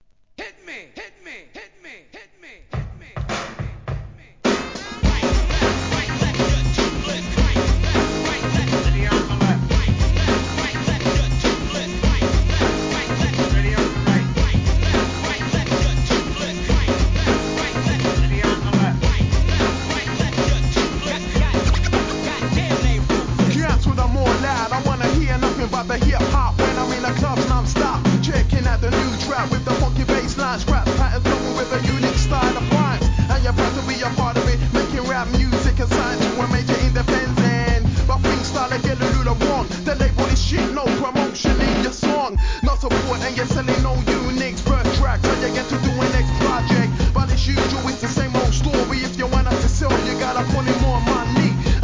HIP HOP/R&B
UK NEW SCHOOL!!!渋いサンプリングのプロダクションに荒々しいRAP